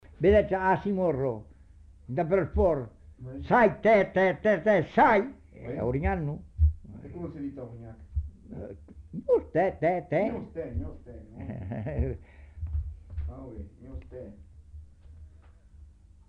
Appel pour les porcs
Aire culturelle : Savès
Genre : expression vocale
Type de voix : voix d'homme
Production du son : crié
Classification : appel au bétail